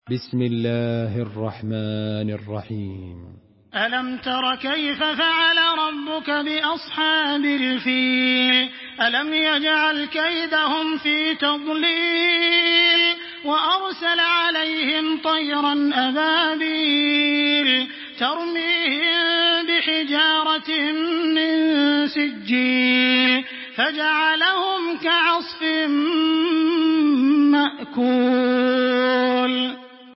تحميل سورة الفيل بصوت تراويح الحرم المكي 1426
مرتل حفص عن عاصم